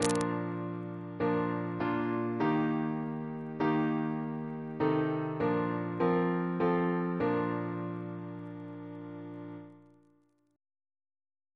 Single chant in B♭ Composer: Thomas Tallis (1505-1585) Reference psalters: ACP: 225 287; H1940: 665; PP/SNCB: 225